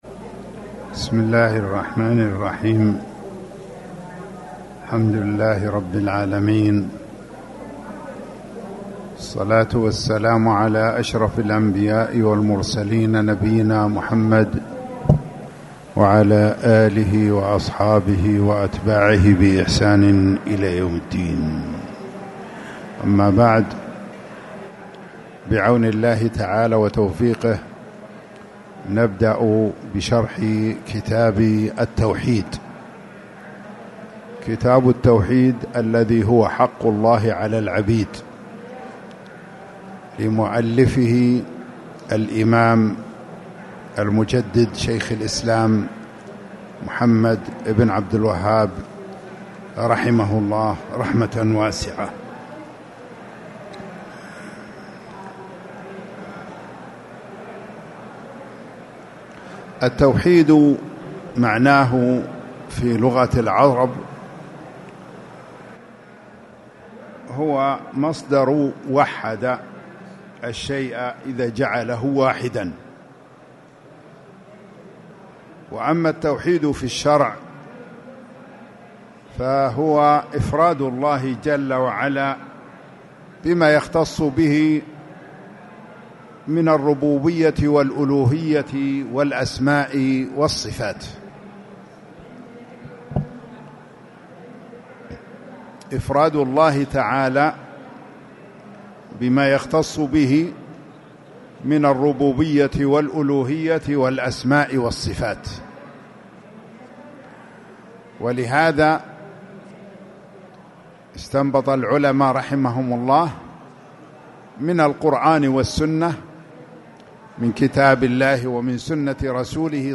تاريخ النشر ١٧ شوال ١٤٣٨ المكان: المسجد الحرام الشيخ